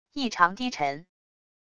异常低沉wav音频